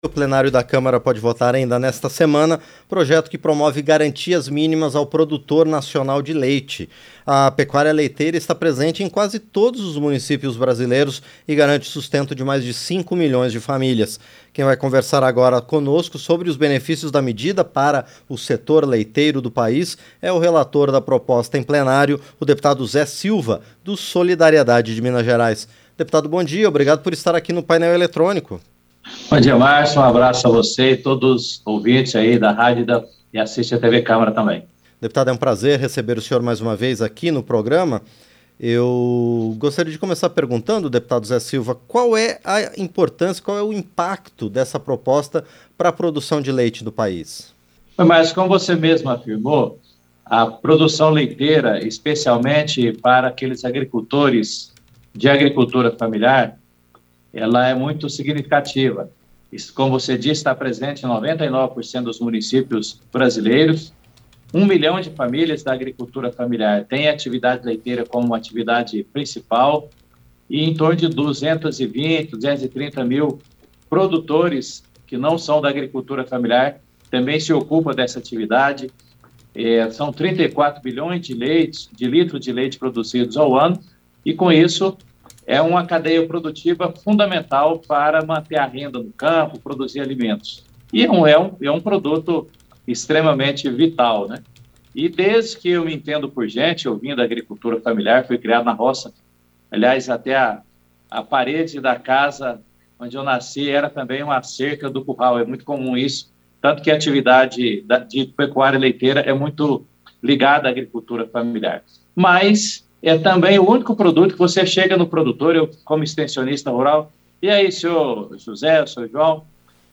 Entrevista - Dep. Zé Silva (Solidariedade-MG)